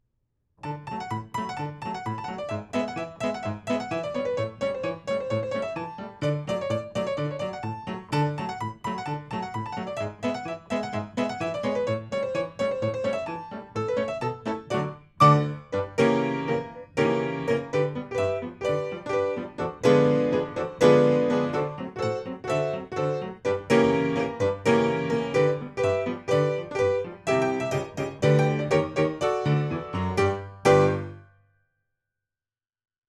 Notes: for piano
Western Intermezzo–B.